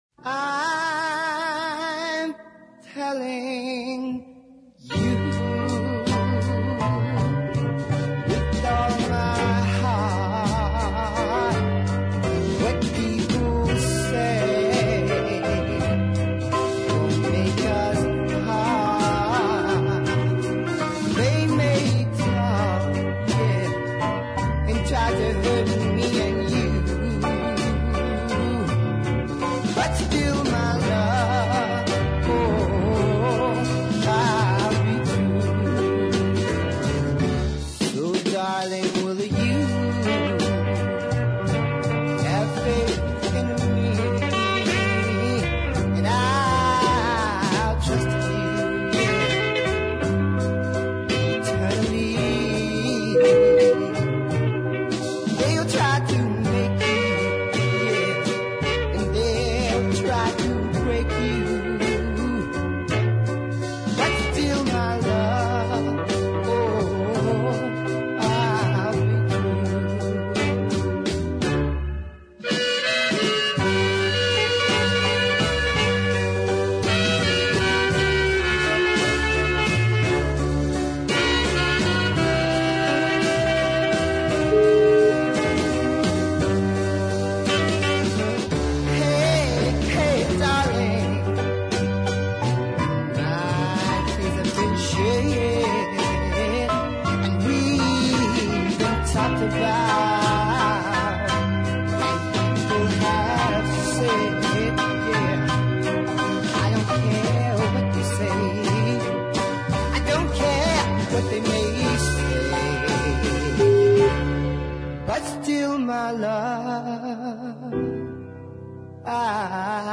is a fine early soul ballad
light tenor